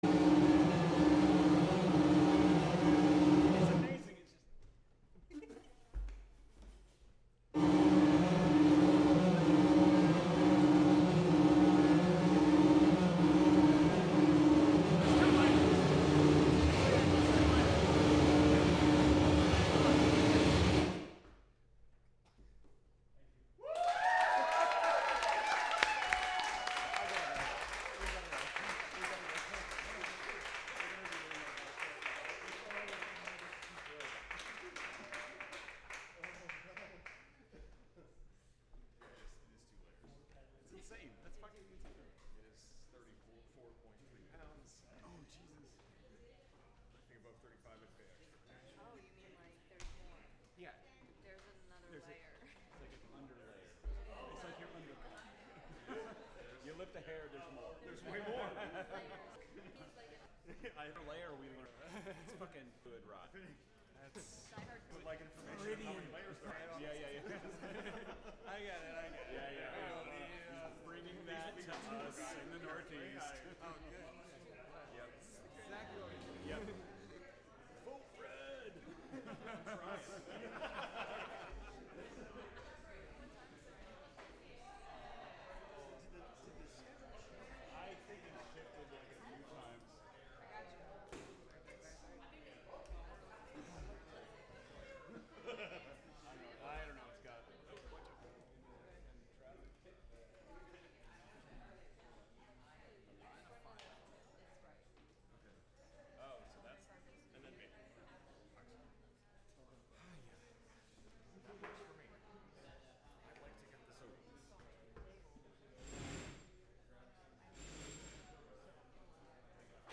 Live from BuddyFest: Buddyfest (Audio)